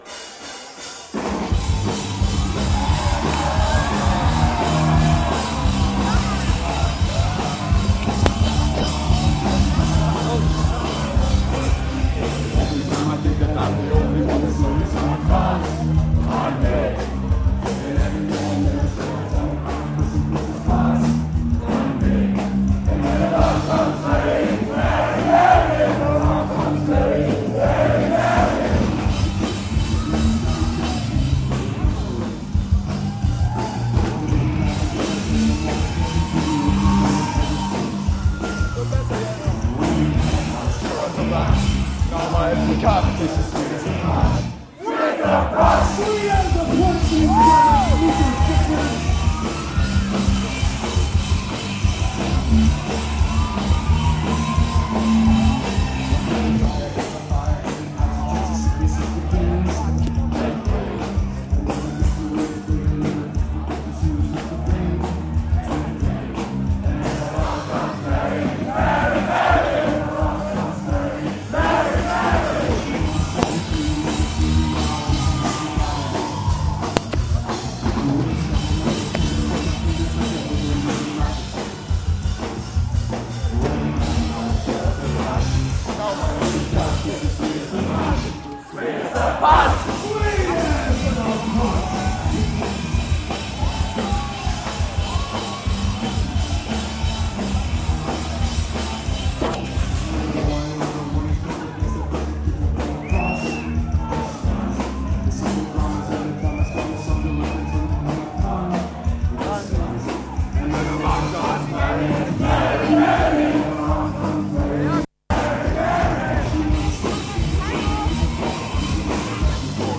Recorder: Sharp IM-DR420H (mono mode)
Microphone: Sony ECM-T6 (mono)